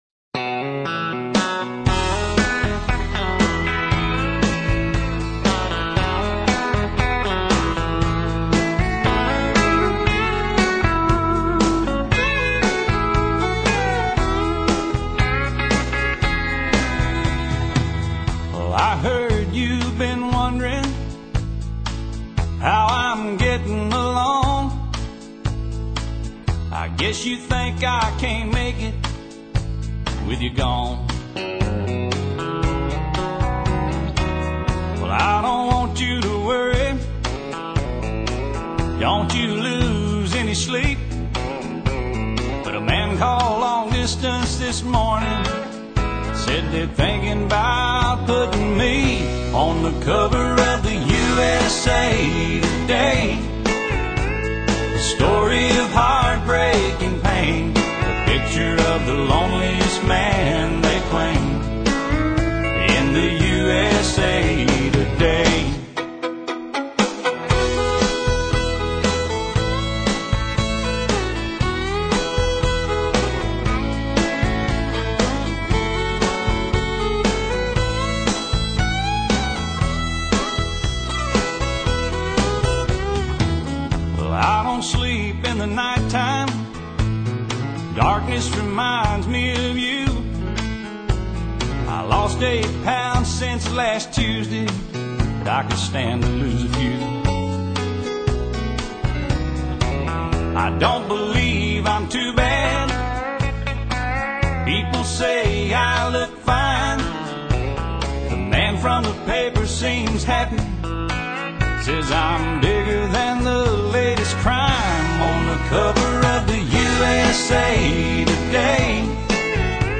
乡村音乐不像纯古典音乐離自己很遥远；也不像摇滚、重金属音乐那样嘈杂。